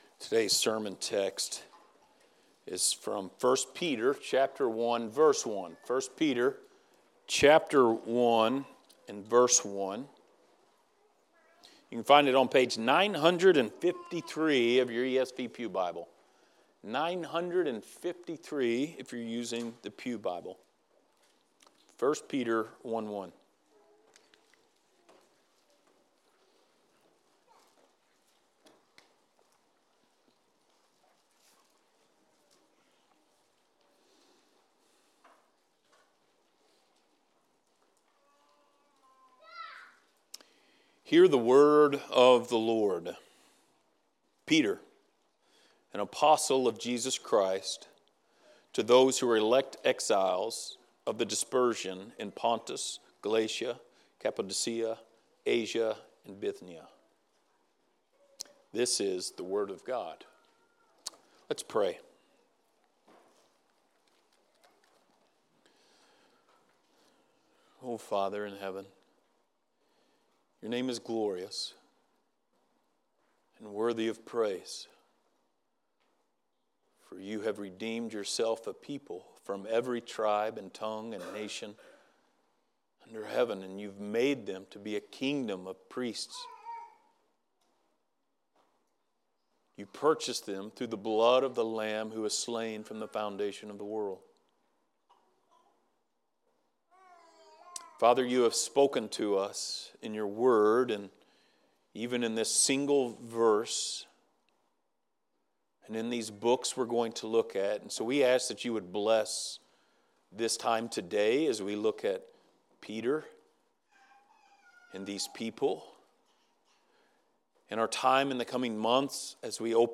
Passage: 1 Peter 1:1 Service Type: Sunday Morning